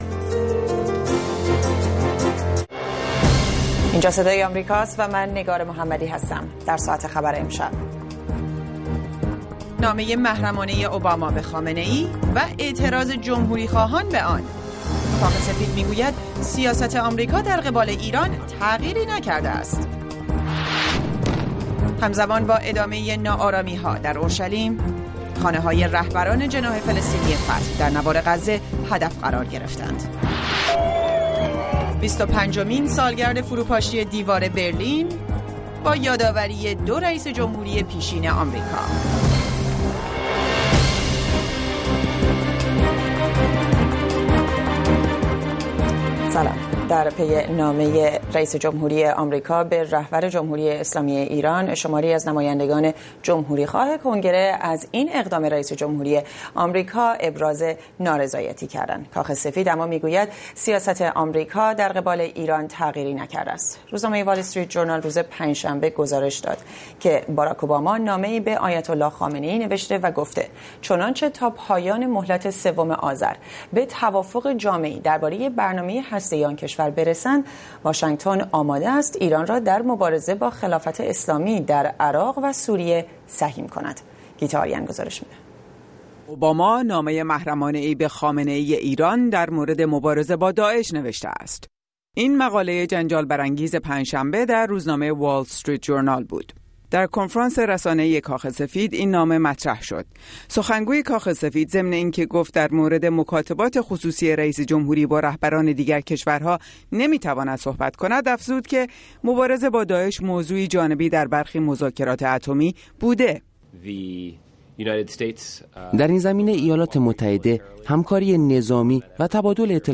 مشروح خبرهای ايران و جهان، بحث و بررسيهای مهمترين رويدادهای خبری روز در گفتگو با خبرسازان، کارشناسان و تحليلگران.